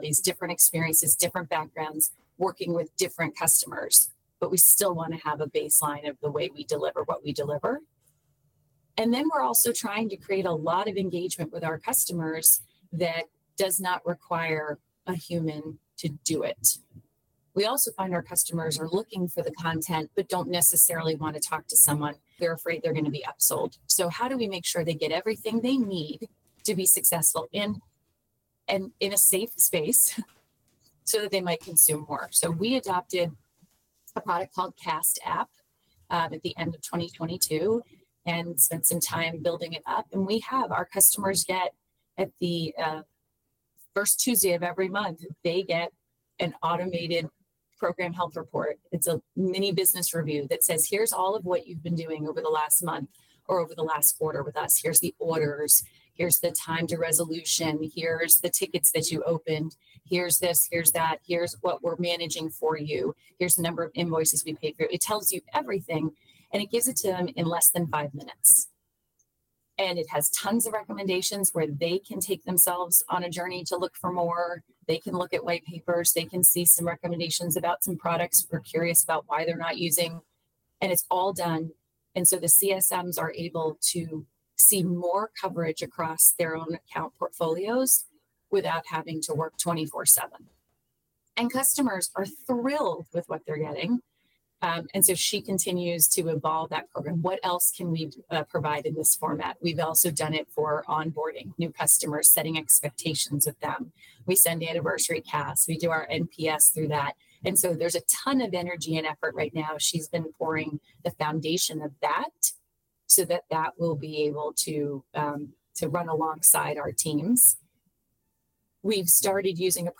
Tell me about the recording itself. ( source: Apple Podcast)